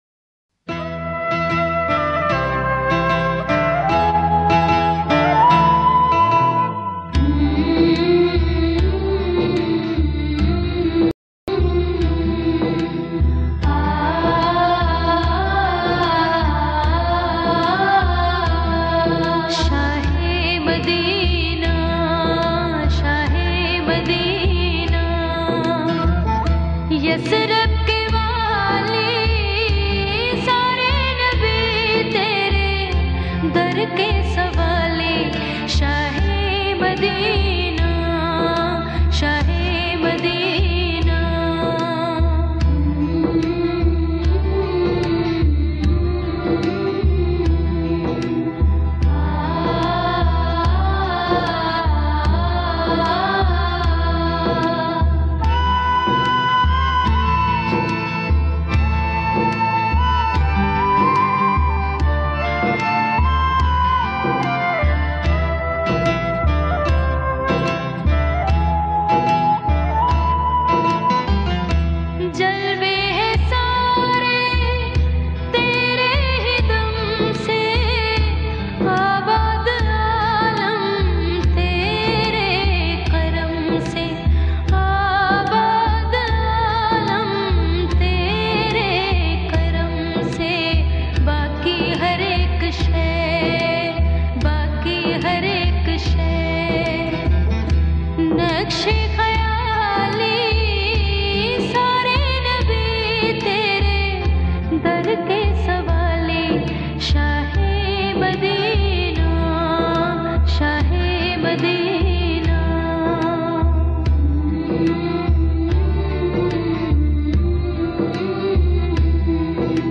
in beautifull voice